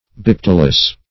Bipetalous \Bi*pet"al*ous\